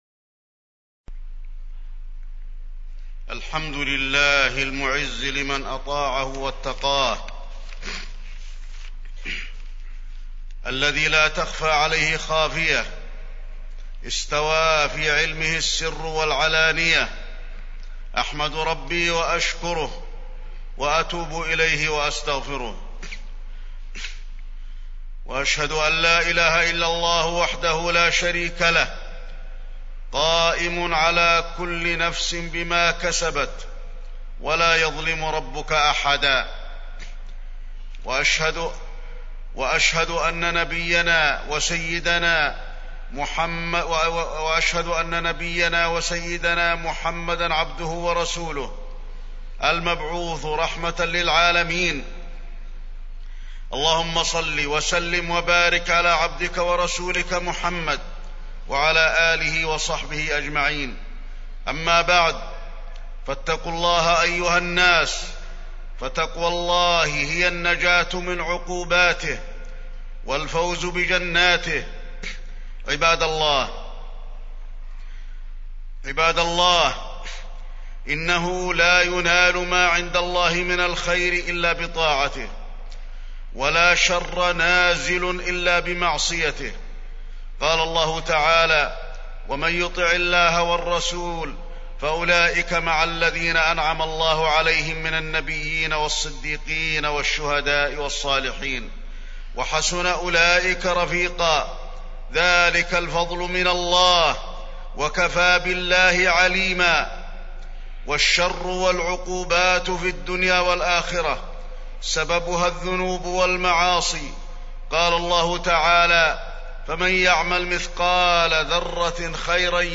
تاريخ النشر ٢٤ صفر ١٤٢٧ هـ المكان: المسجد النبوي الشيخ: فضيلة الشيخ د. علي بن عبدالرحمن الحذيفي فضيلة الشيخ د. علي بن عبدالرحمن الحذيفي إجتناب المعاصي